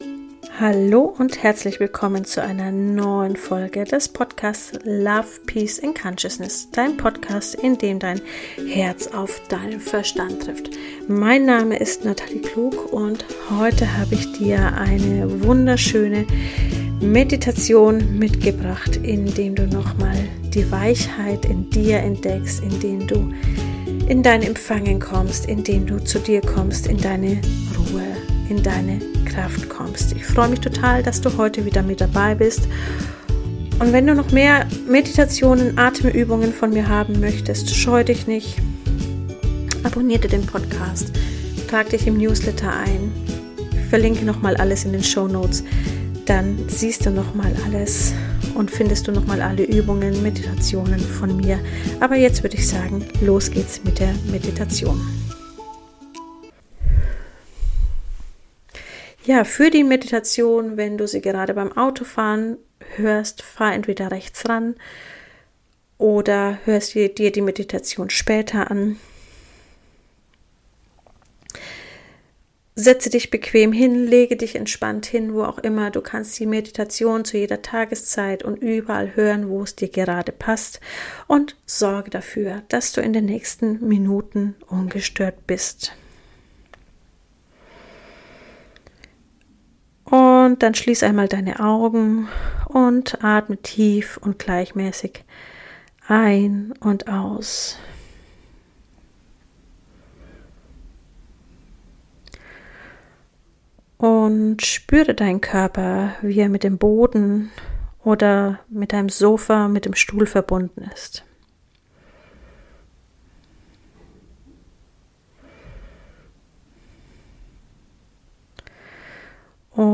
Meditation für weibliche Energie – Zyklus, Empfangen & innere Kraft